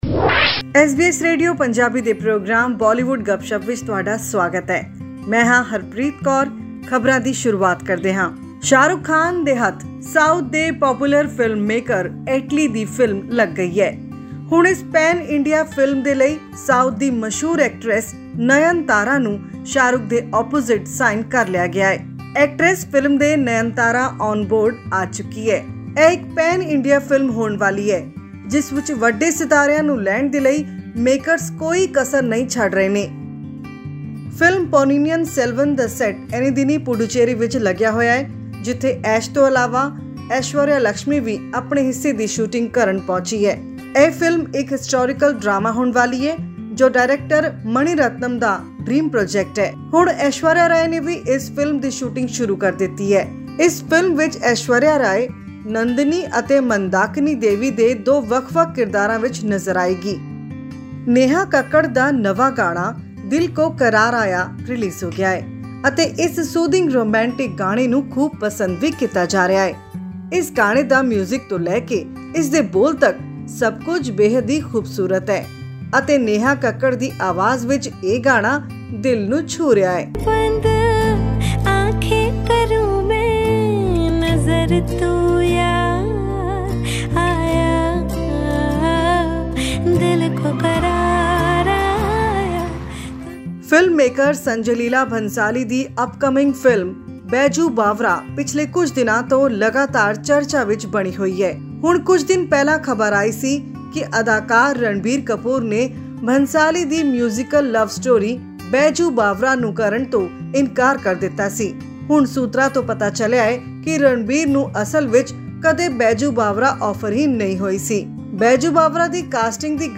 Many new films have started their production in Mumbai and surrounding areas. To get latest updates on upcoming films and songs, listen to our weekly Bollywood News bulletin.